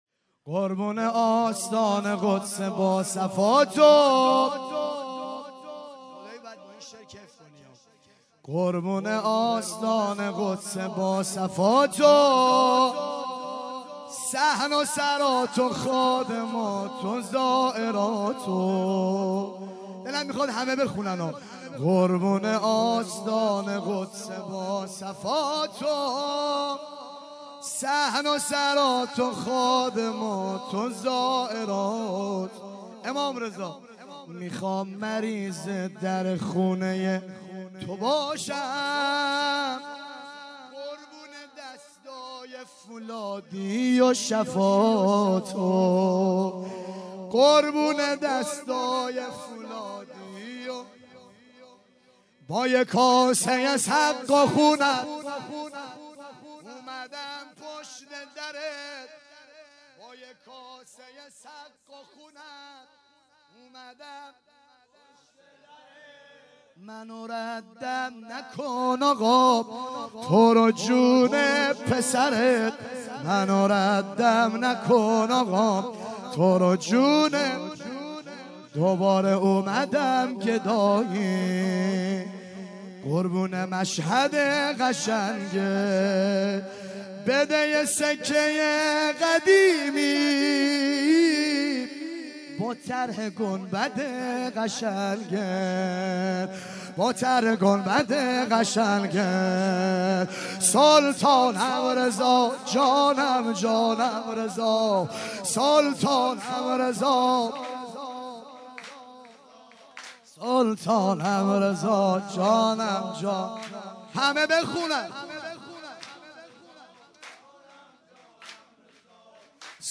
6- قربون آستان قدس باصفات و - زمینه